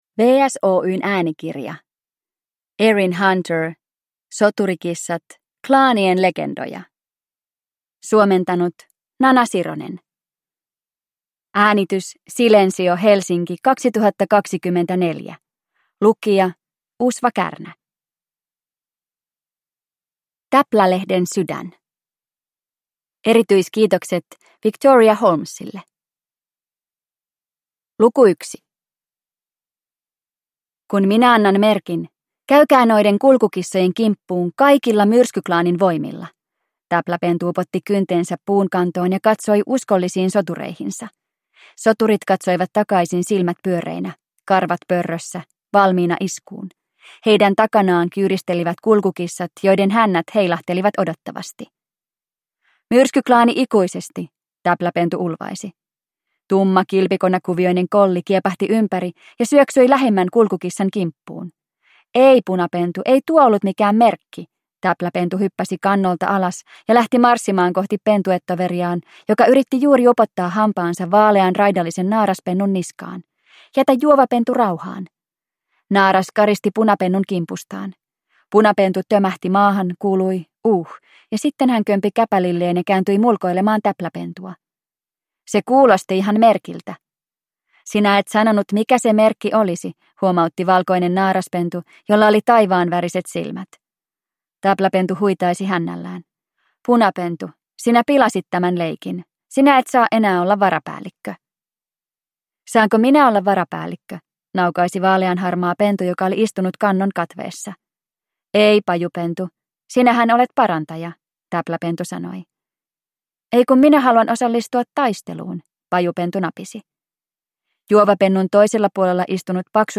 Soturikissat: Klaanien legendoja – Ljudbok